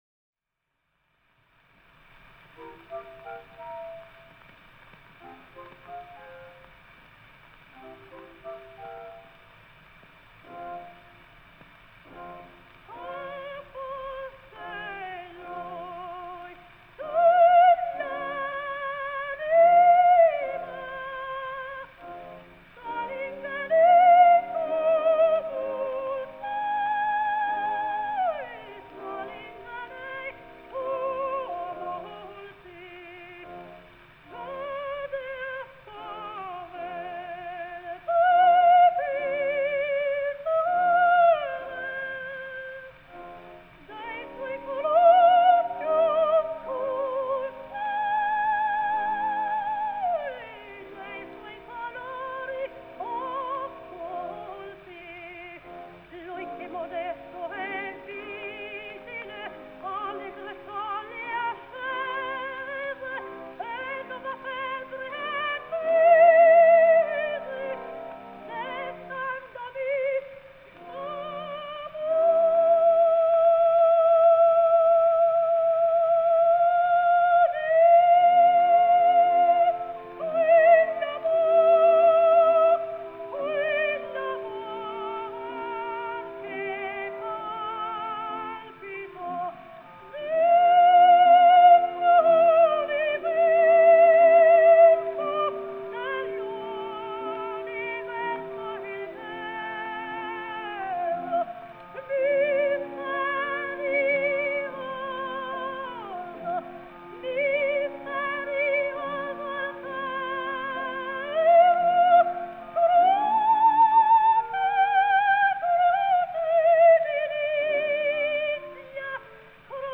ТЕТРАЦЦИНИ (Tetrazzini) Луиза (29, по др. данным, 28 VI 1871, Флоренция - 28 IV 1940, Милан) - итал. певица (колоратурное сопрано).